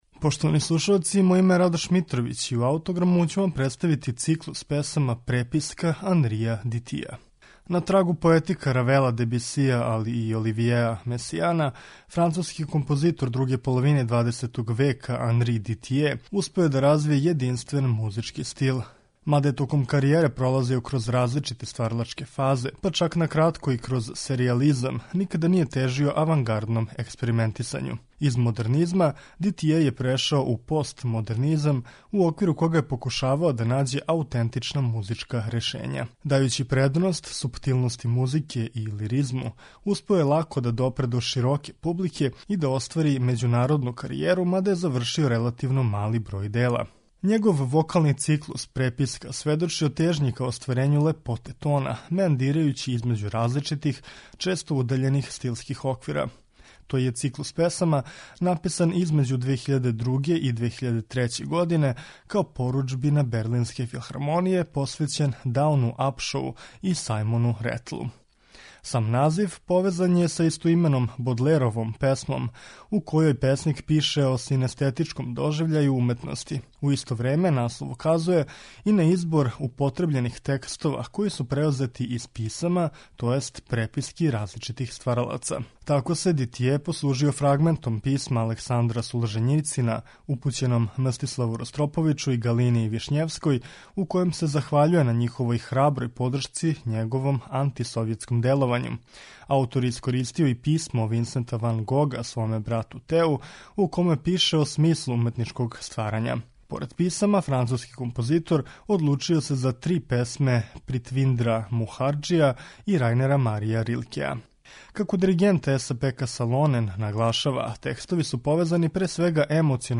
вокални циклус
Циклус песама за сопран и оркестар